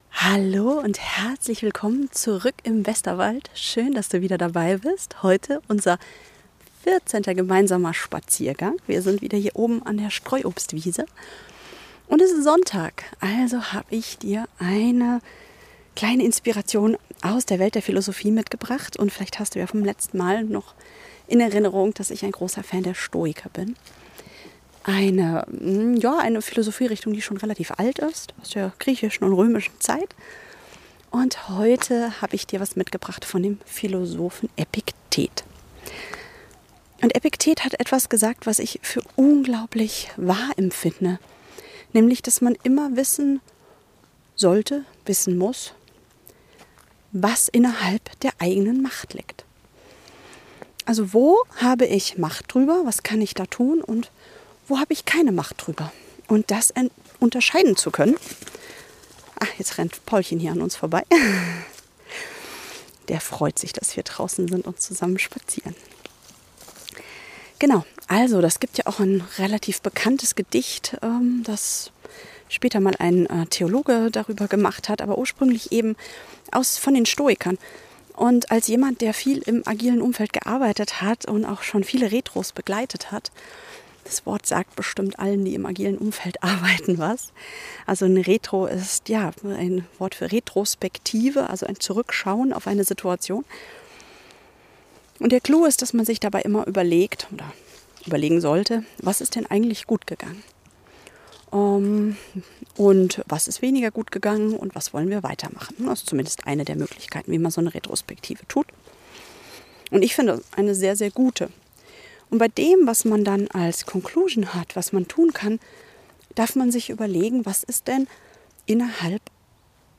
Spaziergang zur Streuobstwiese, wo ich dir eine kraftvolle